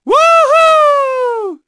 Neraxis-Vox_Happy4_kr.wav